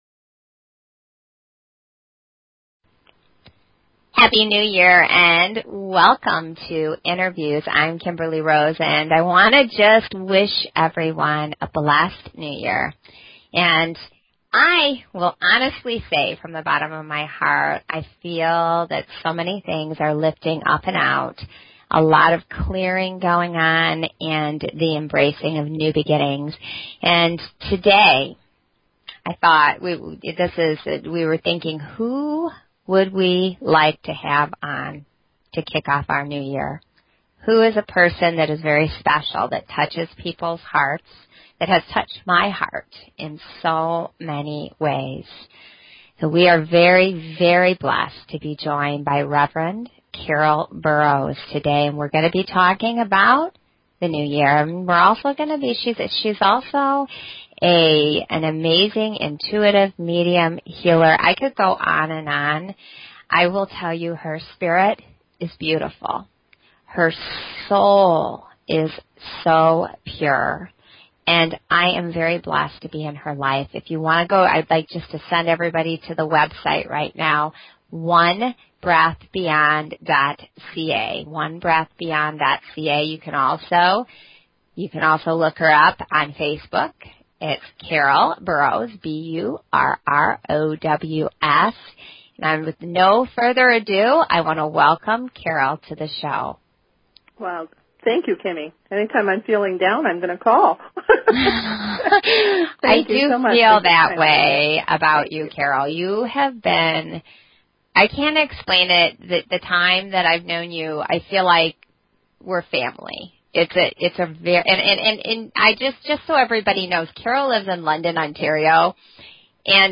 Talk Show Episode
Talk Show